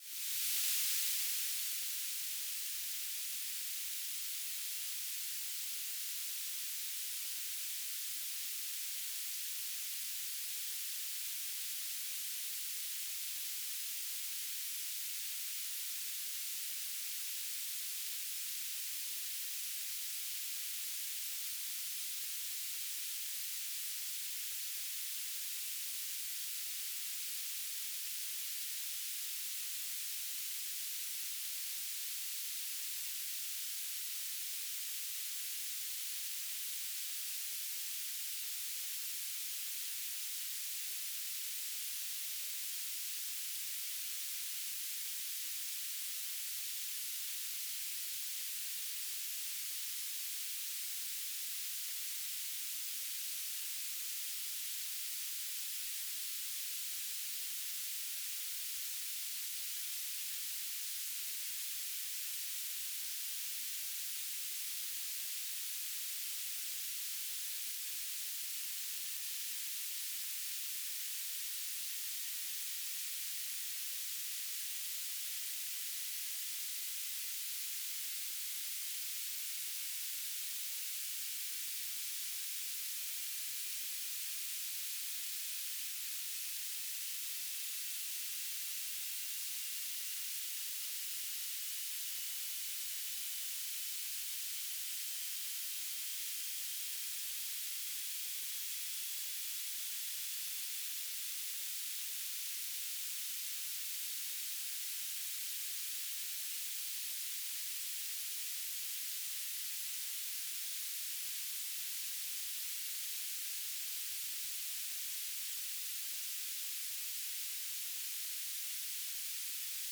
"station_name": "UNB Earth Station",
"transmitter_description": "Mode U - BPSK1k2 - Beacon",
"transmitter_mode": "BPSK",